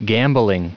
Prononciation du mot gambling en anglais (fichier audio)
Prononciation du mot : gambling